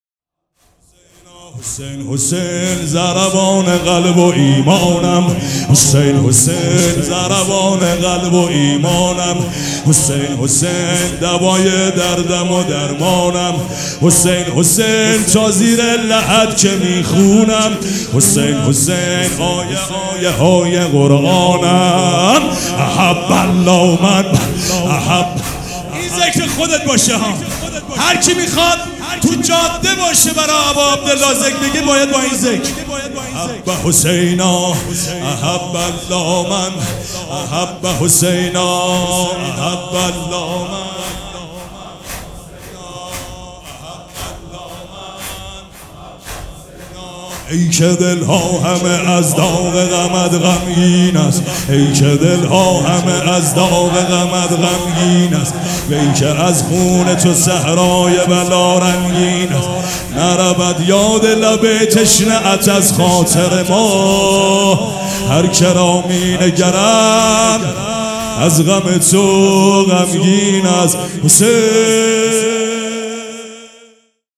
سبک اثــر واحد
مراسم عزاداری شب سوم